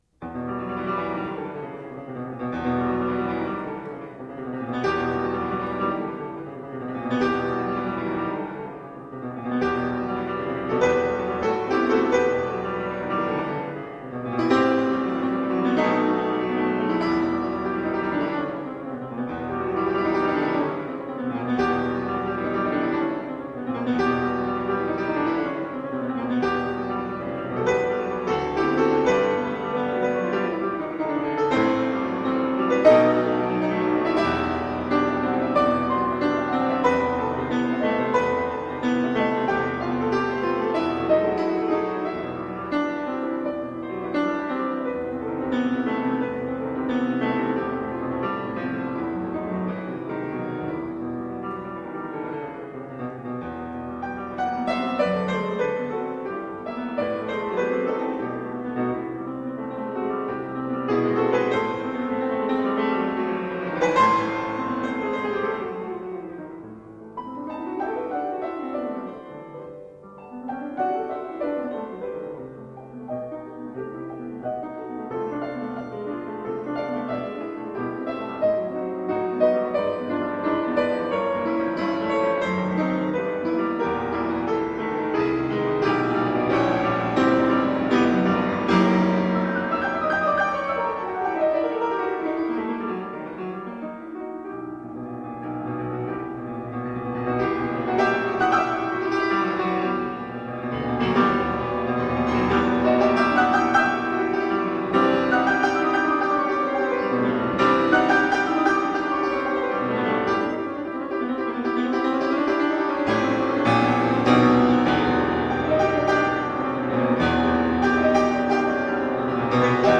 19th century classical piano.
Classical, Romantic Era, Instrumental
Classical Piano